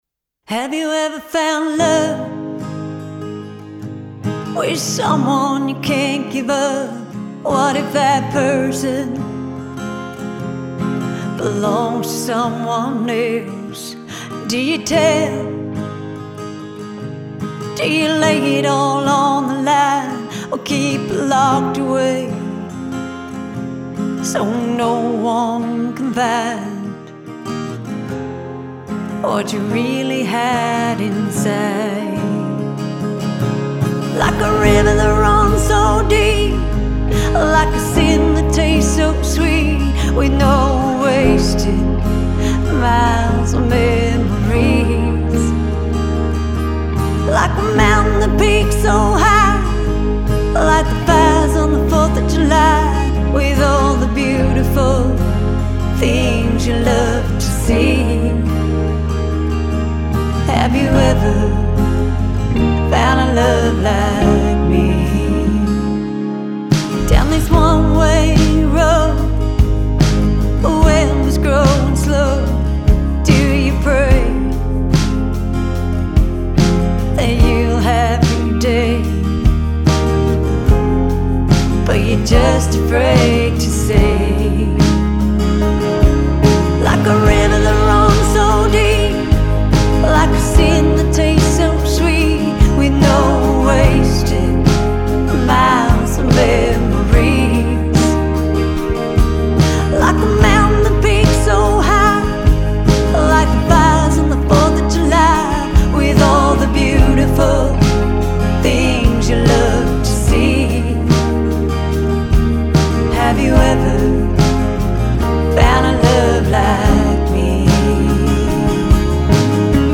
country/acoustic rock duo
guitarist
vocalist